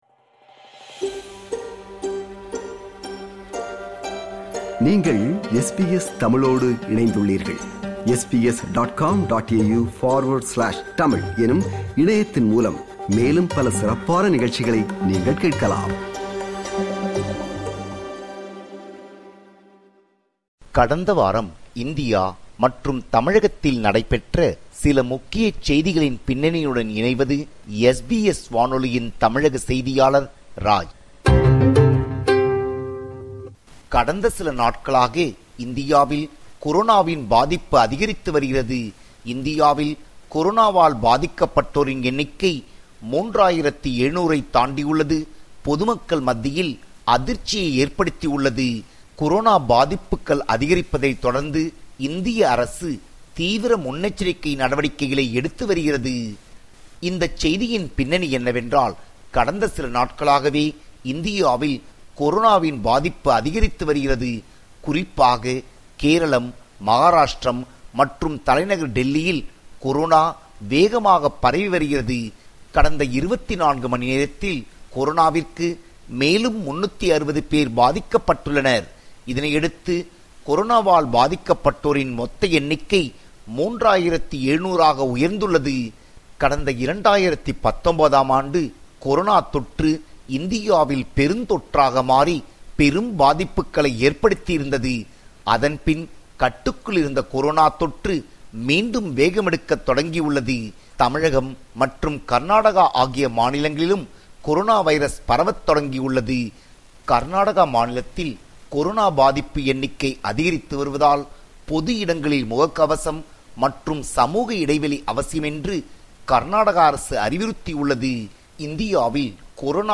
இந்தியா & தமிழ்நாடு: கடந்தவார நிகழ்வுகளின் தொகுப்பு